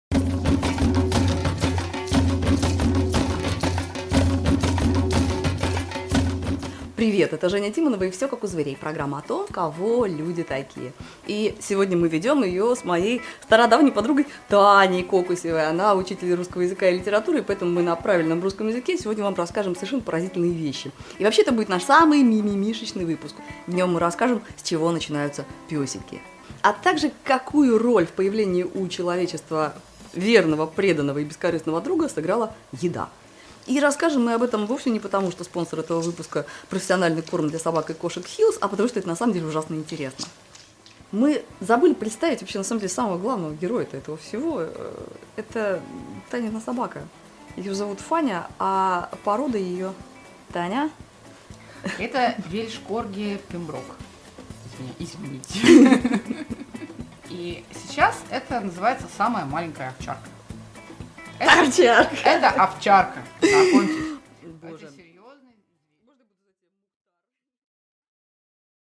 Аудиокнига Как мы завели собаку?